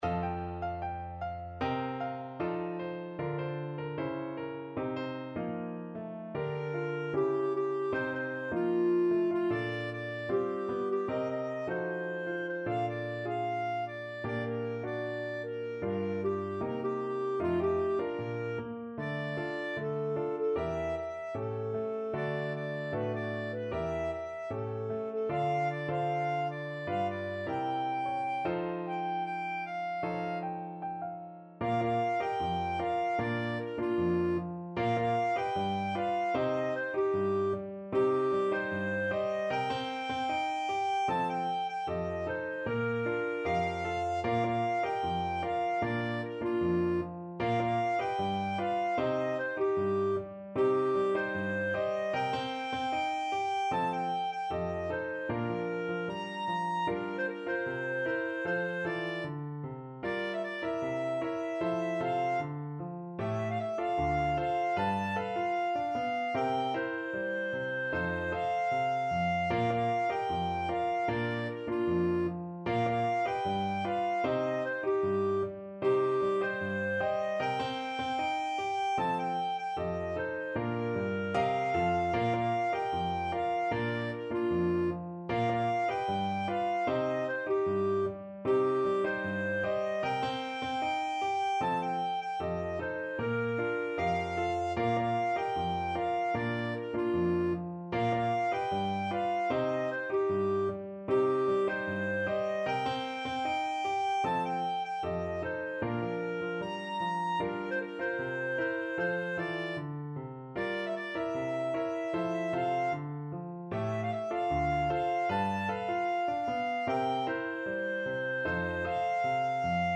2/2 (View more 2/2 Music)
Pop (View more Pop Clarinet Music)